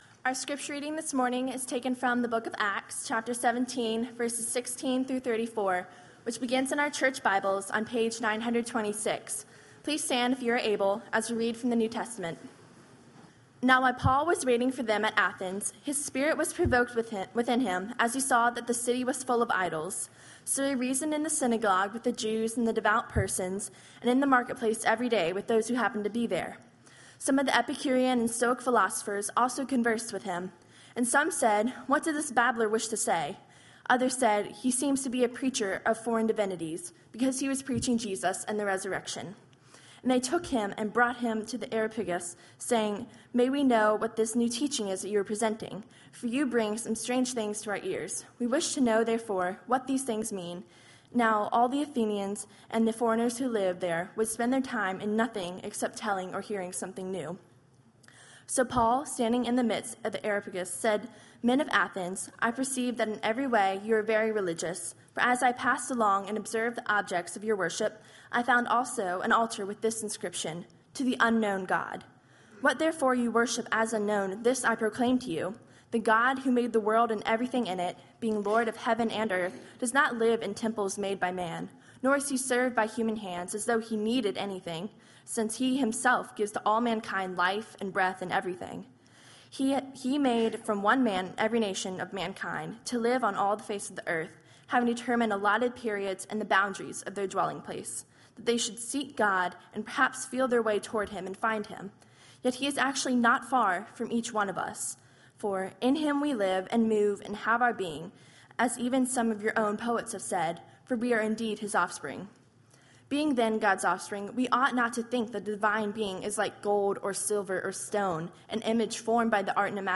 sermon-audio-8.2.15.mp3